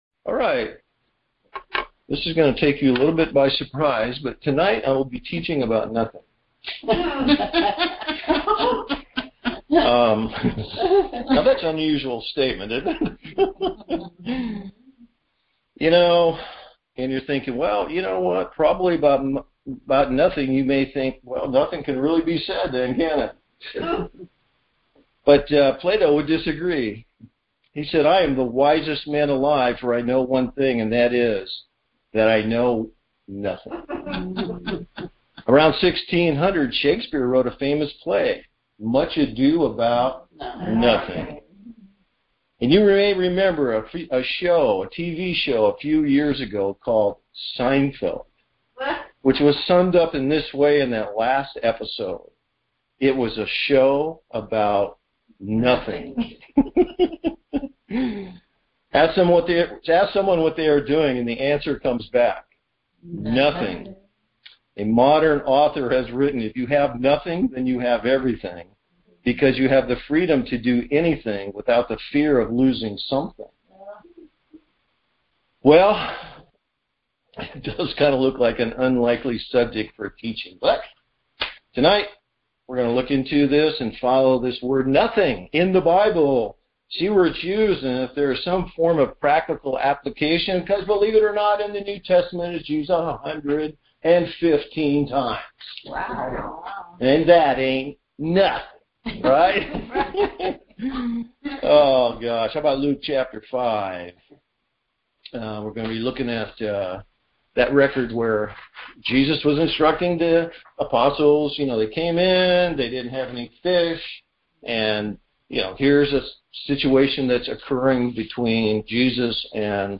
(teaching was blank between 10:58 - 12:28, below is the text of the missing portion) Finally, one more verse, the nothing of self assessment .